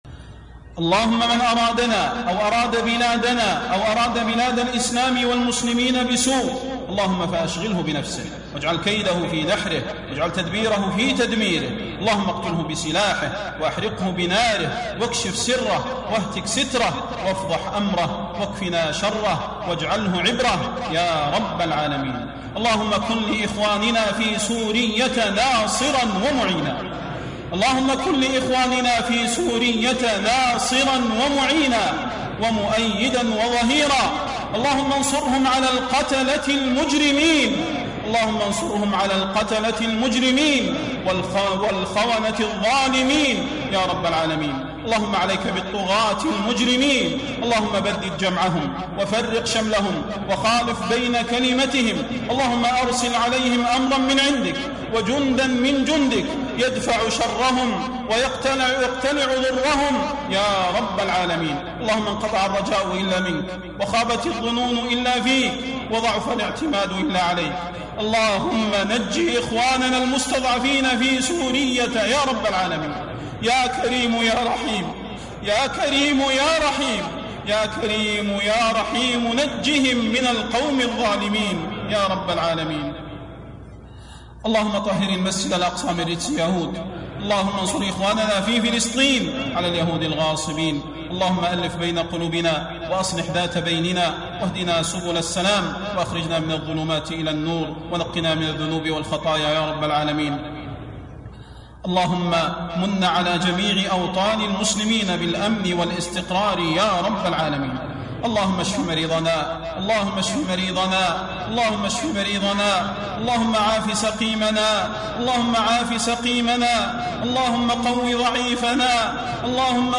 Ansarallah دعاء الشيخ صلاح البدير لأهل سوريا